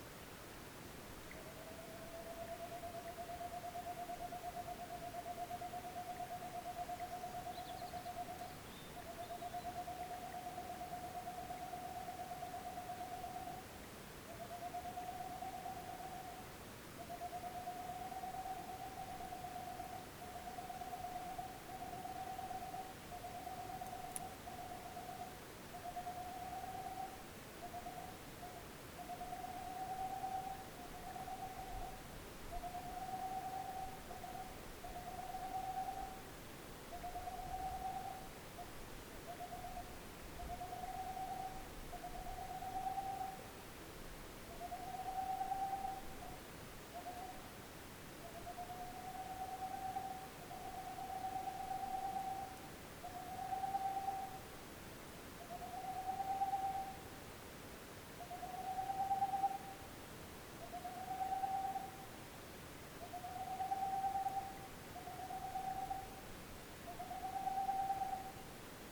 Chant-chouette-de-Tengmalm-2.mp3